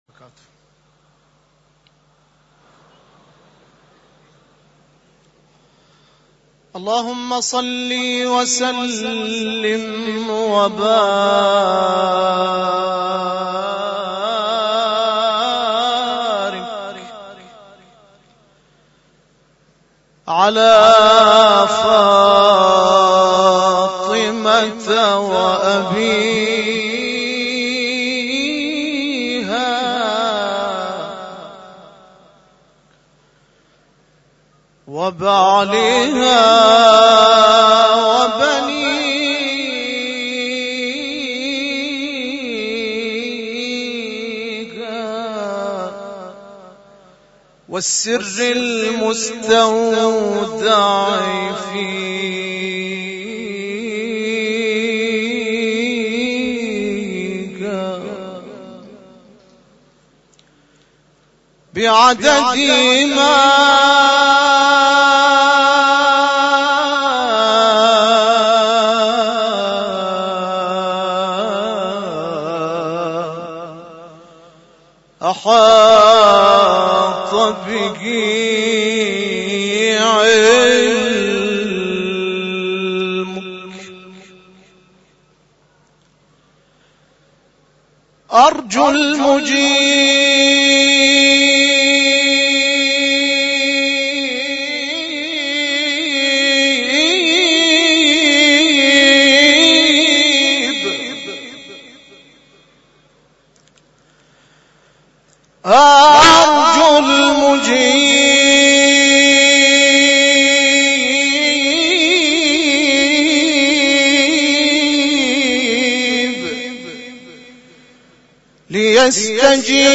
ابتهال روز فطر